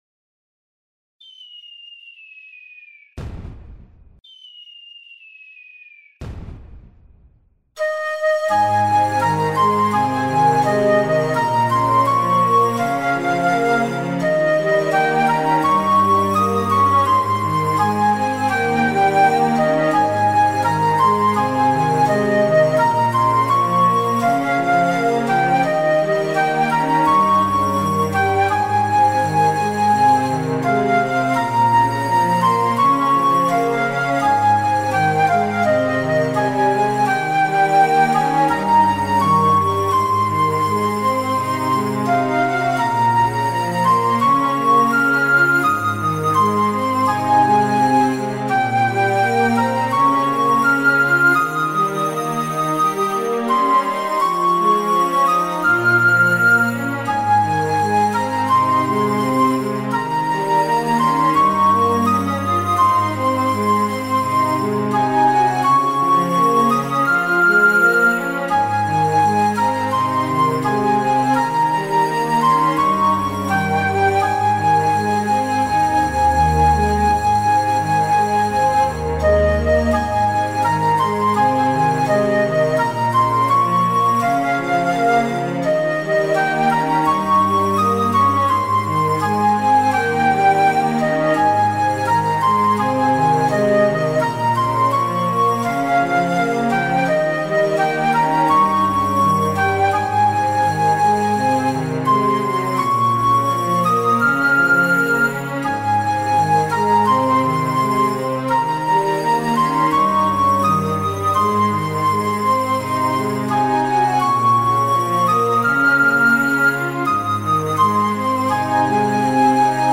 BGM
クラシックロング明るい穏やか